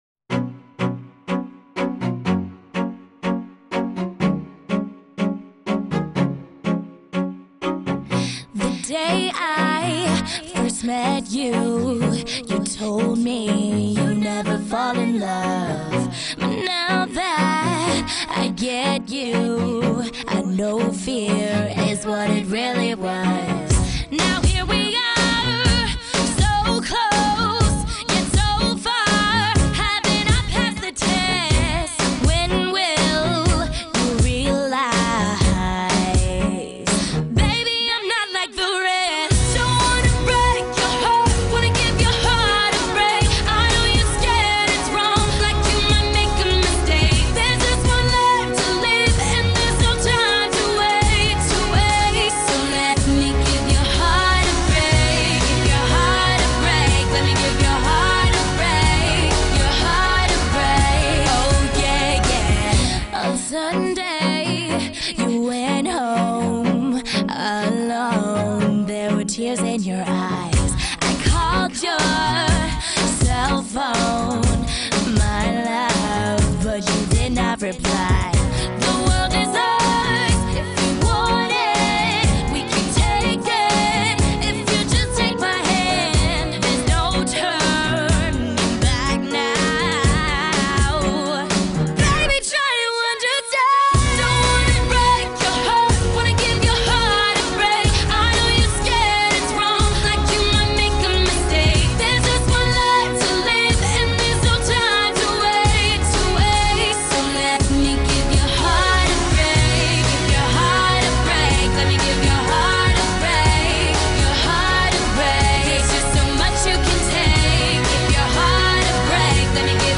Tags: chill dance workout party